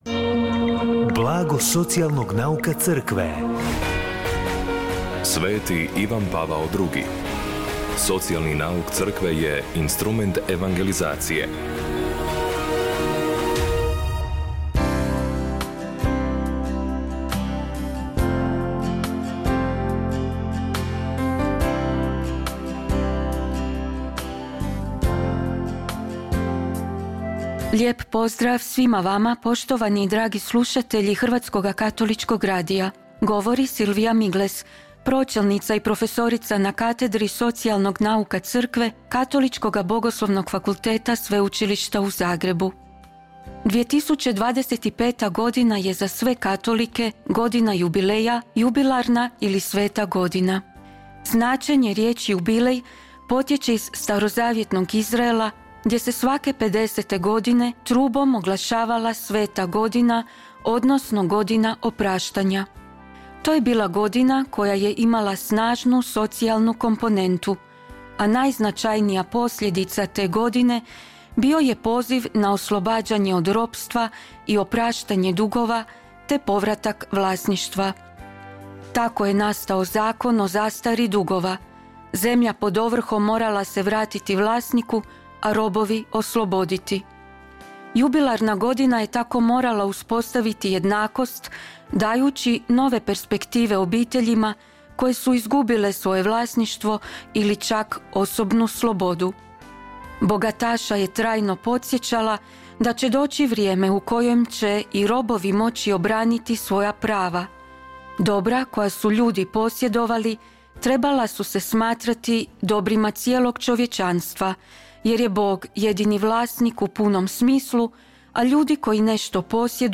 Emisiju na valovima HKR-a “Blago socijalnog nauka Crkve” subotom u 16:30 emitiramo u suradnji s Centrom za promicanje socijalnog nauka Crkve Hrvatske biskupske konferencije.